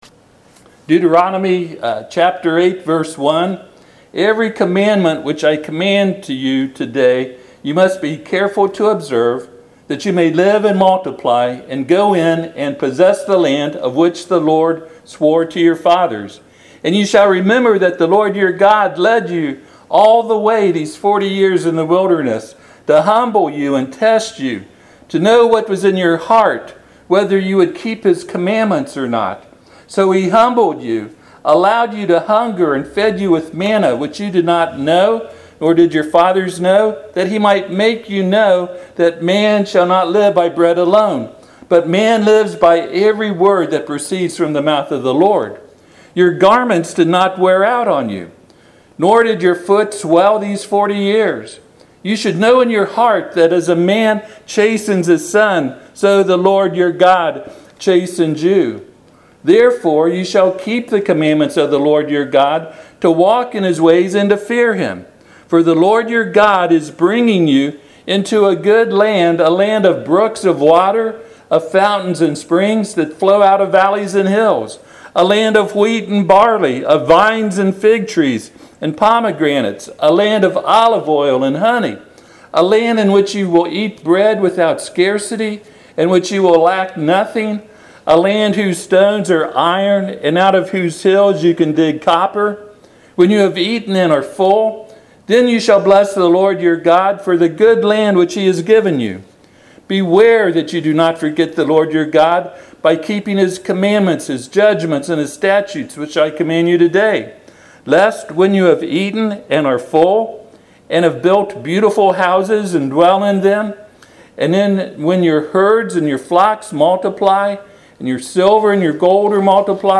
Passage: Deuteronomy 8:1-20 Service Type: Sunday PM